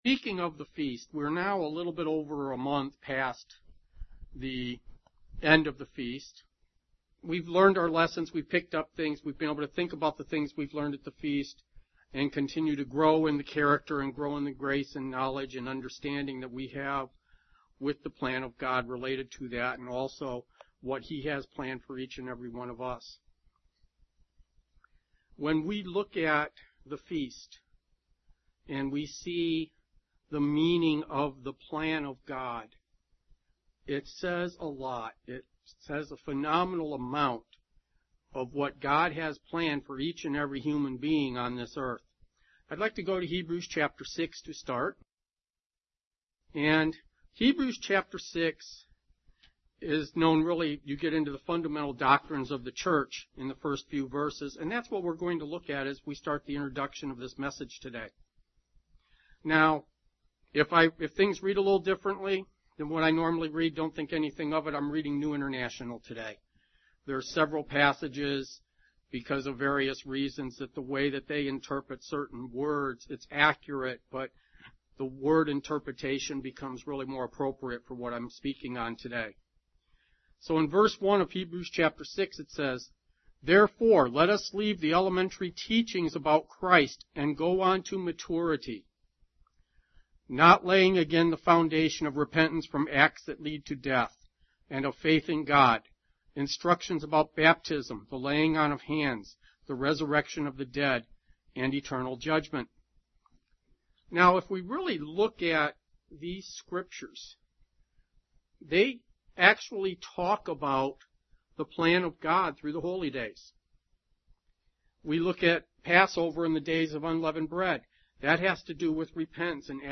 Print Four types of Maturity discussed are: 1) Physical, 2) Mental, 3) Emotional, 4) Spiritual UCG Sermon Studying the bible?